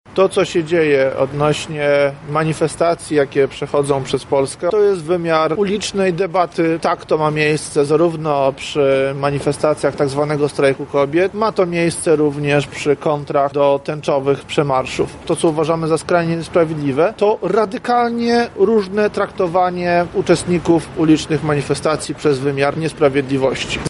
Mamy do czynienia z wymiarem niesprawiedliwości- mówi prezes Ruchu Narodowego Robert Winnicki: